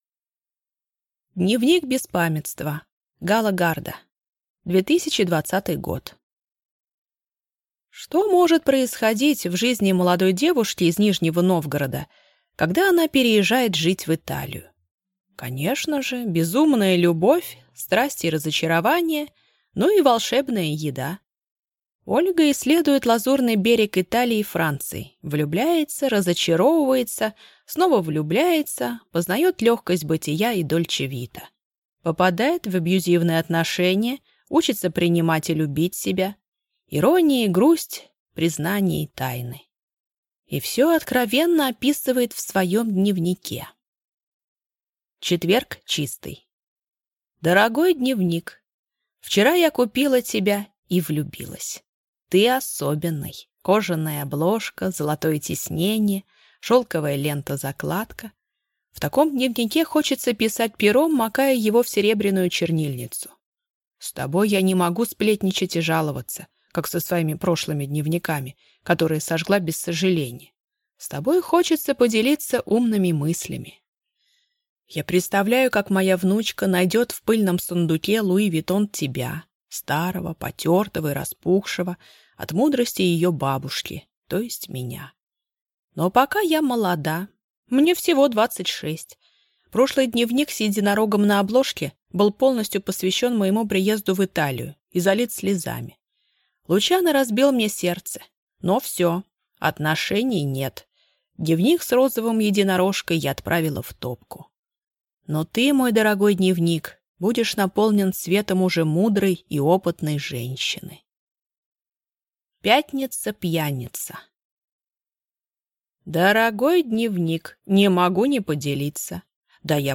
Аудиокнига Дневник Беспамятства | Библиотека аудиокниг